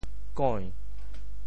茧（繭） 部首拼音 部首 艹 总笔划 9 部外笔划 6 普通话 jiǎn 潮州发音 潮州 goin2 白 中文解释 茧 <名> 许多昆虫幼虫在化蛹前包围身体大部分的由丝组成的外皮,在其中化蛹。